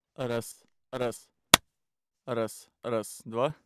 Помехи в тракте
Есть тракт: Микрофон Neumann u87 ai -> Предусилитель Drawmer MX60 -> Digidesign 96 I/O -> Pro Tools 10 Во время записи периодически возникают помехи в виде хруста, исчезают при подаче высокоамлитудного сигнала в микрофон, например...